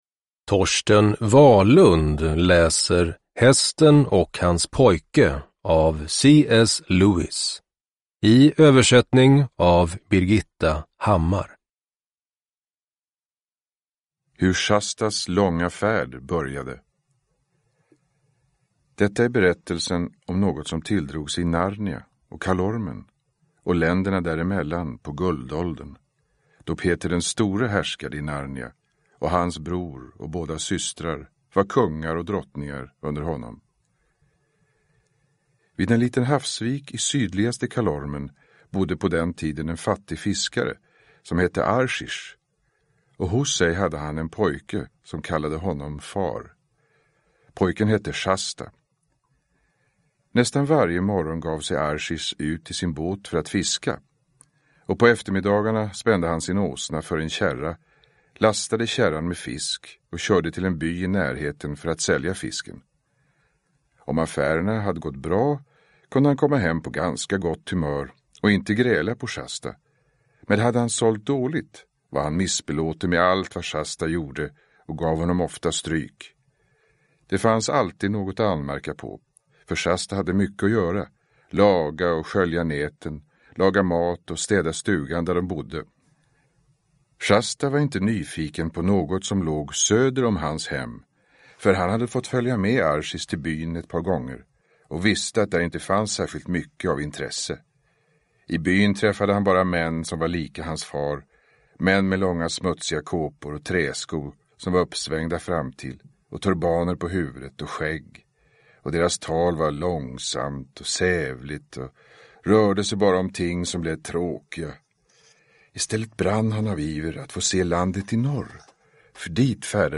Hästen och hans pojke – Ljudbok – Laddas ner
Uppläsare: Torsten Wahlund